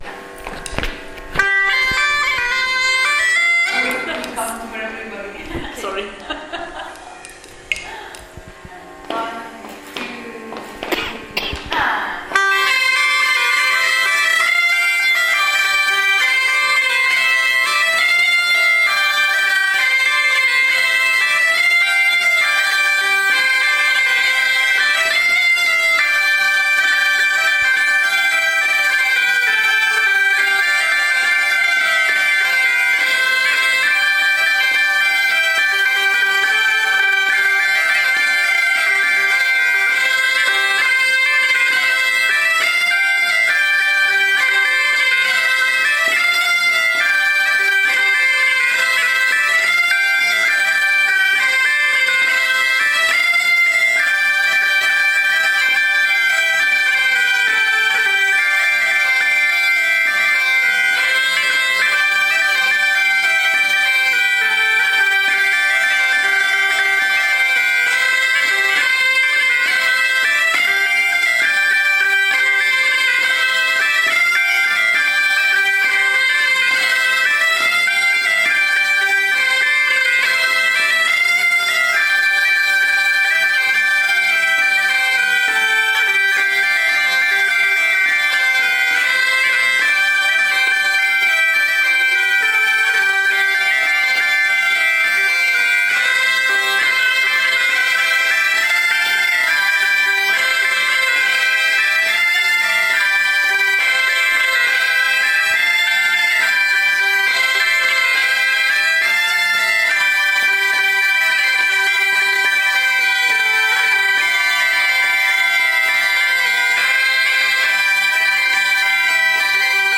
Doedelzakcursus
Hoe klinkt dat nou, zo’n kudde doedelzakkers? Nou, zo dus: